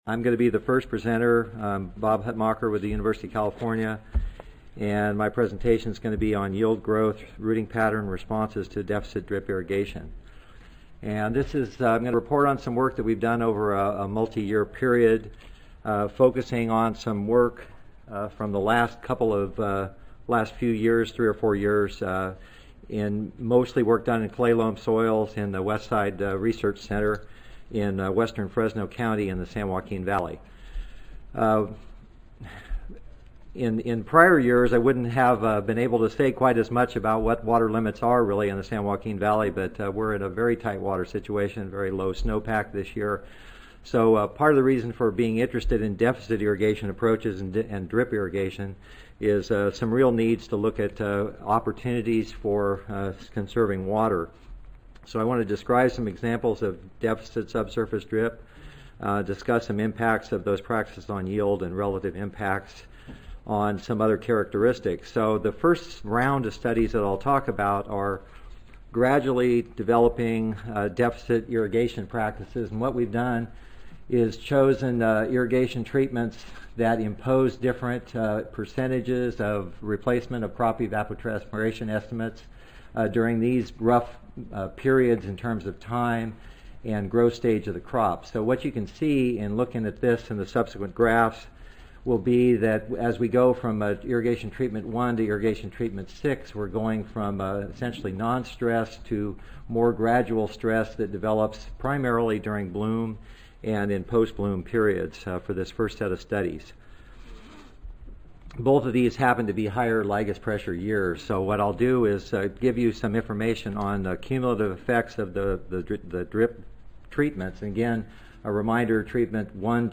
Extension Audio File Recorded Presentation